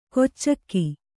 ♪ koccakki